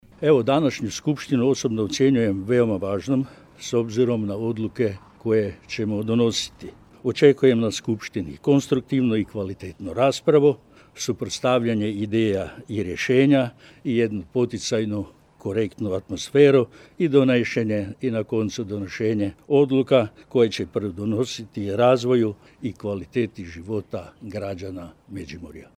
Pred početak, predsjednik Skupštine Dragutin Glavina i izjavama za medije istaknuo je: